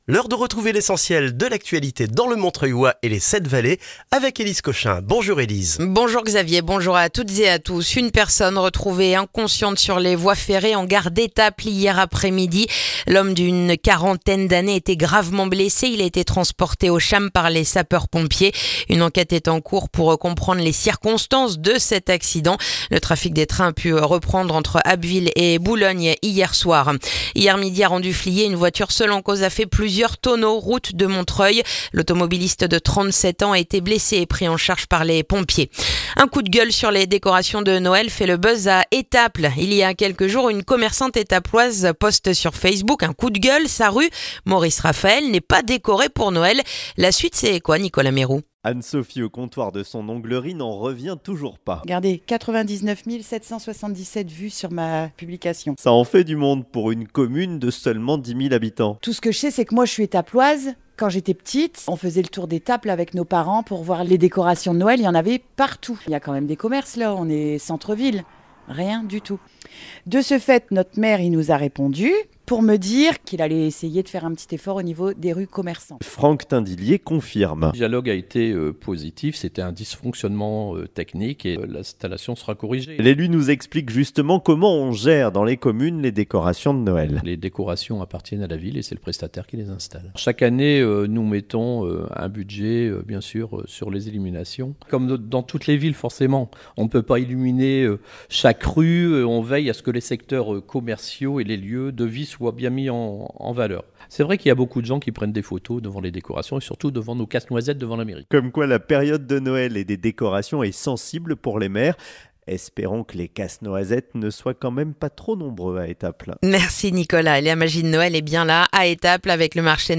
Le journal du jeudi 4 décembre dans le montreuillois